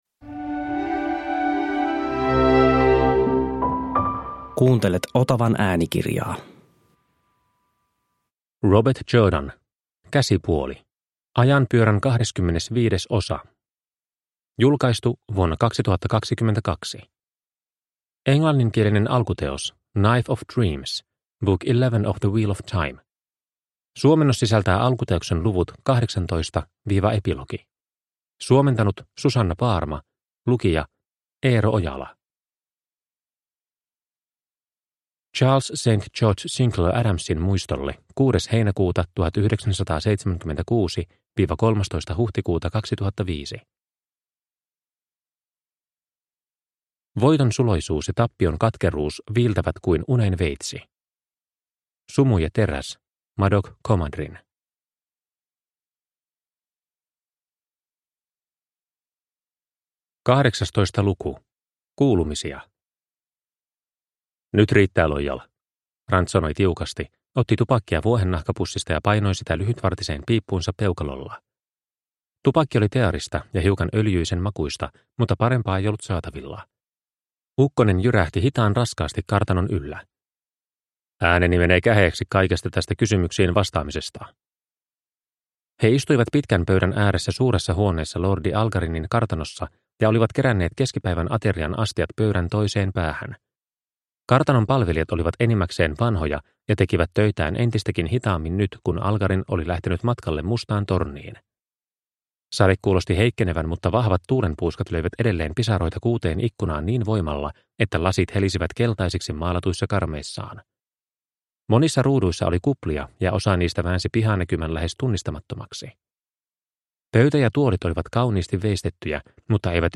Käsipuoli – Ljudbok – Laddas ner